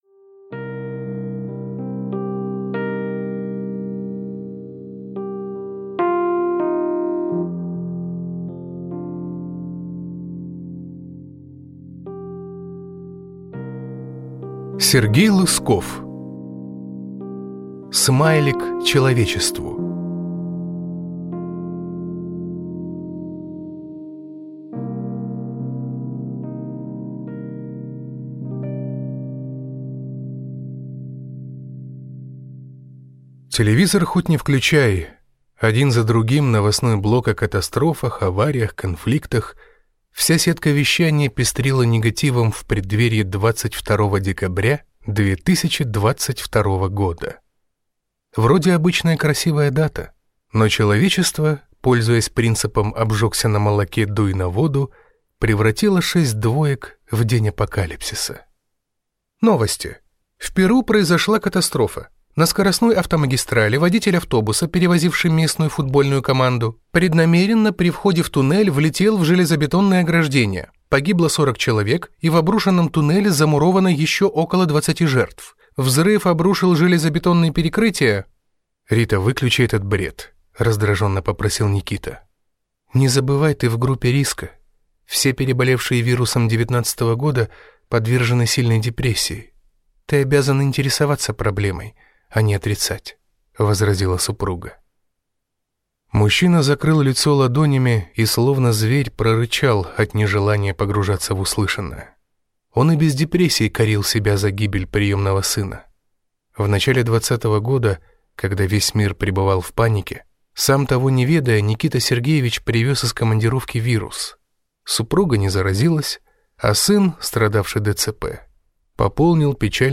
Аудиокнига Смайлик человечеству | Библиотека аудиокниг